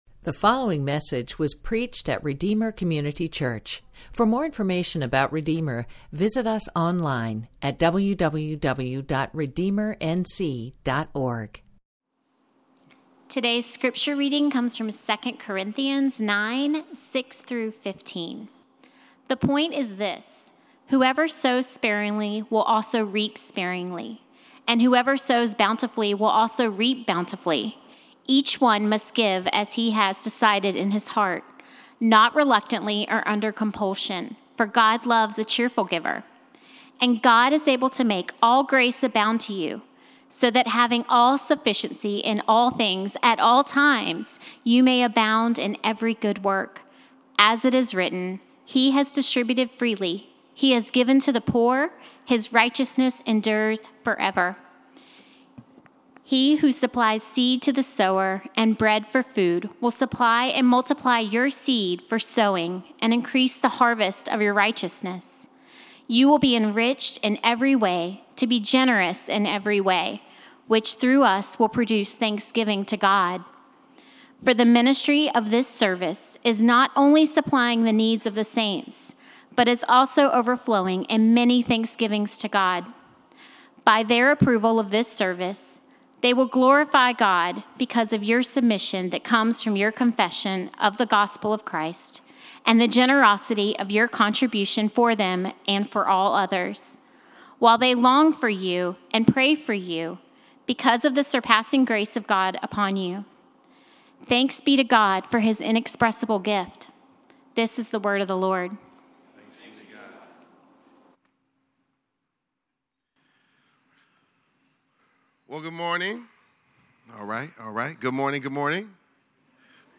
Sermons - Redeemer Community Church